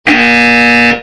Efectos de sonido
SONIDO TONO CELULAR PERDER INCORRECTO NO VALIDO
sonido_tono_celular_-perder-incorrecto-no-valido.mp3